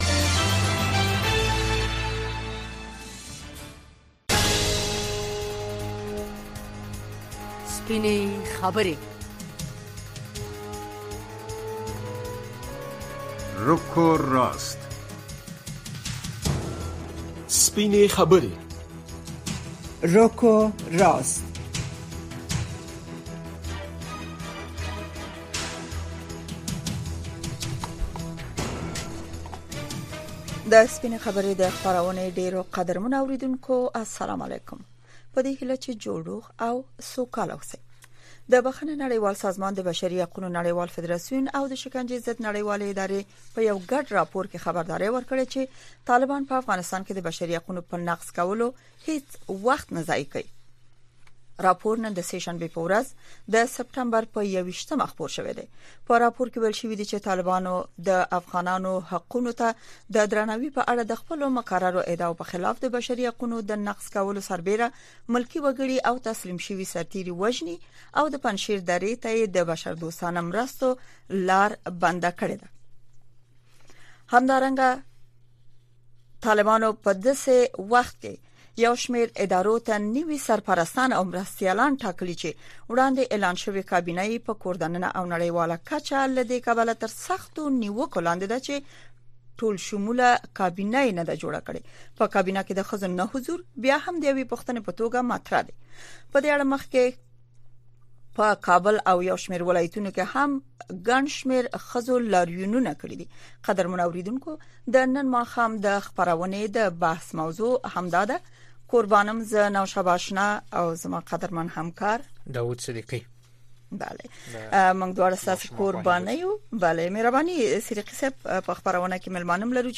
د ورځې په خبرونو اومسایلو د نظر د خاوندانو سپینې خبرې او د اوریدونکو نظرونه